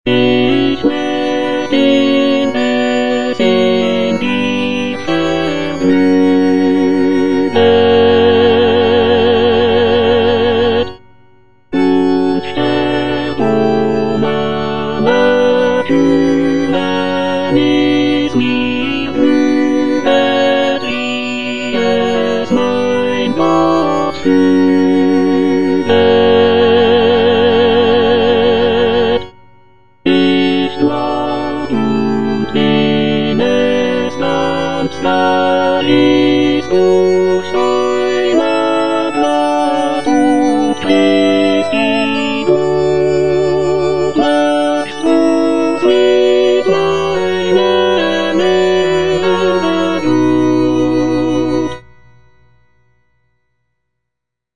The work features a joyful and optimistic tone, with the solo soprano expressing gratitude for the blessings in her life. The text explores themes of contentment, trust in God, and the acceptance of one's fate. The cantata is structured in six movements, including recitatives, arias, and a closing chorale.